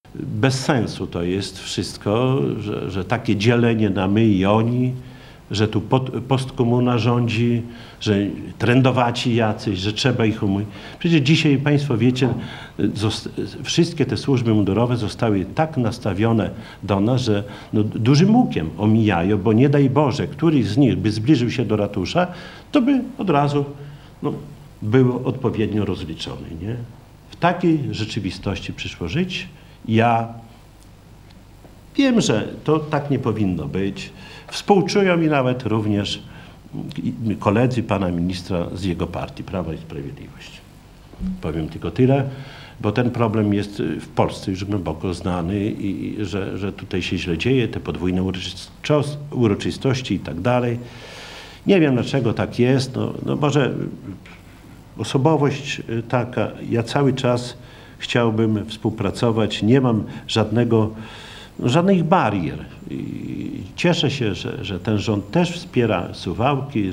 Dziś (10.10), podczas konferencji prasowej, w odpowiedzi na pytania Radia 5, Czesław Renkiewicz, prezydent miasta stwierdził, że frustracja ministra narasta, bo opanował wszystko, ale nie ma do końca władzy nad ratuszem, co uwiera i boli.